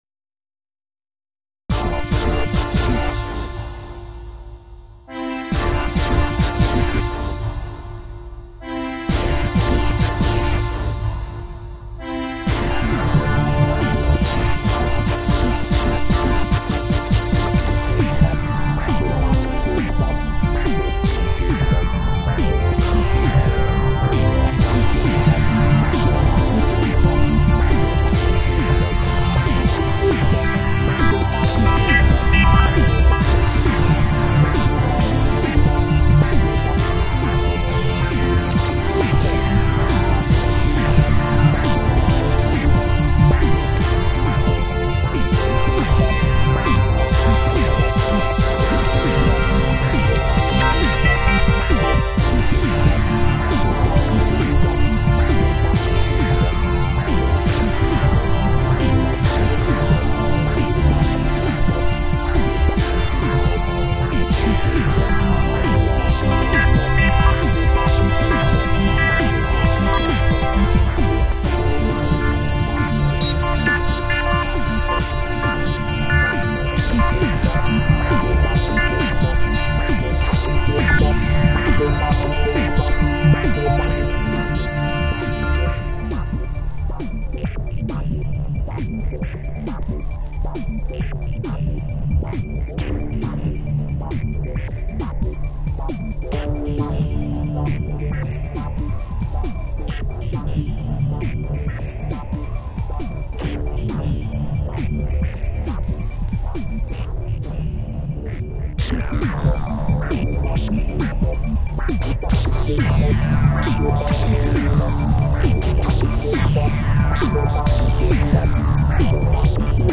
Roland FANTOM G -
další z mých hudebních variací na Roland Fantom G sample